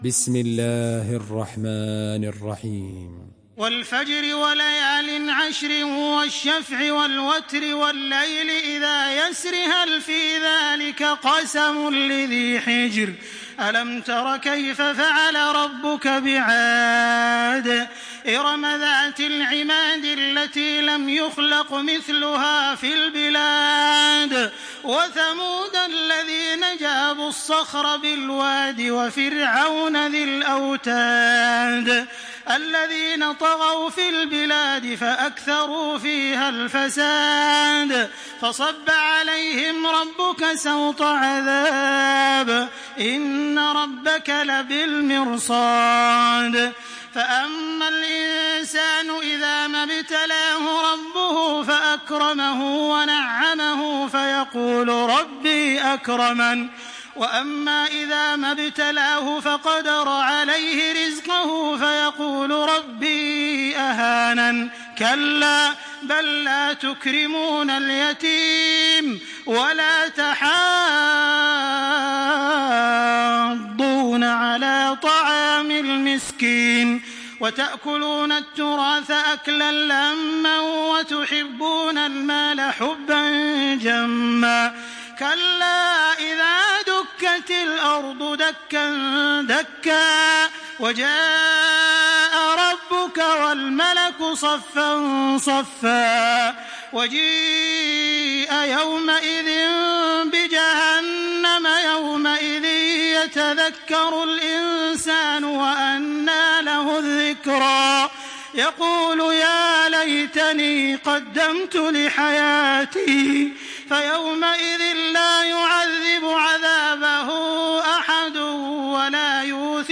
سورة الفجر MP3 بصوت تراويح الحرم المكي 1426 برواية حفص
مرتل